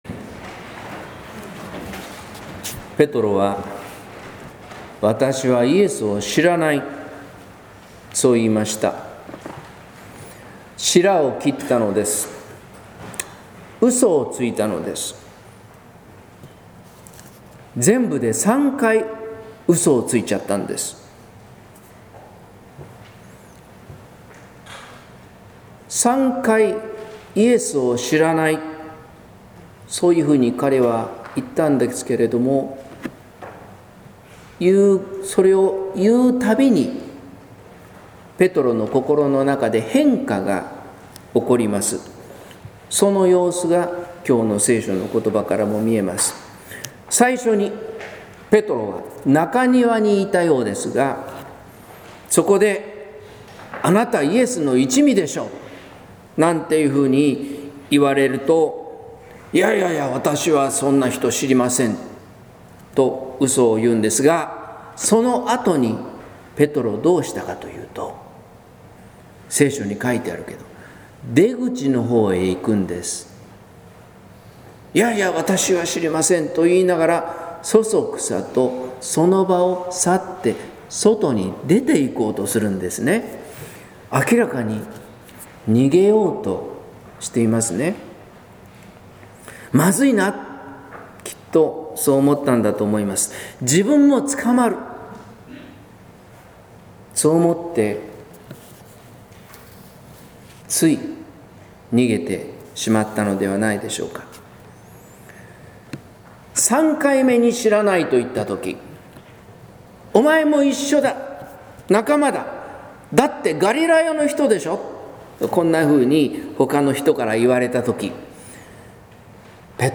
説教「しらを切る人のために」（音声版）